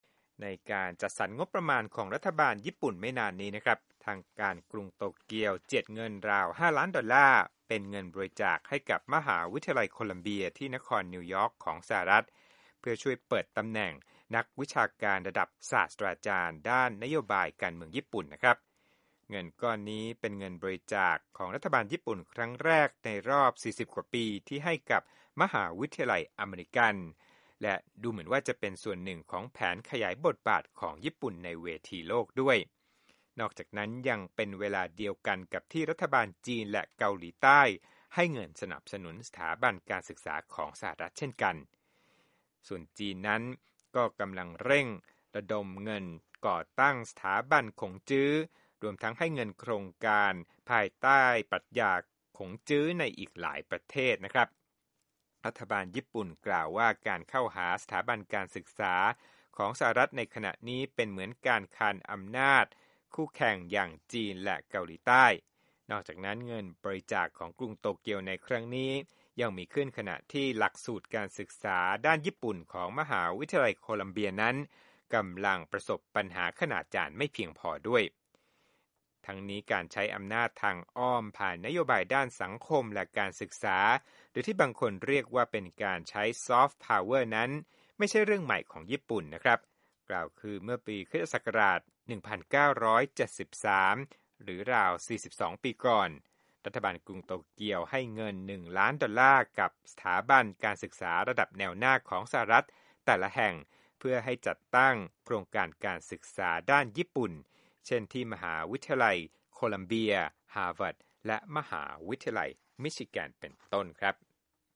Education News
โปรดติดตามรายละเอียดจากคลิปเรื่องนี้ในรายการข่าวสดสายตรงจากวีโอเอ